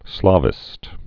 (slävĭst) or Slav·i·cist (slävĭ-sĭst)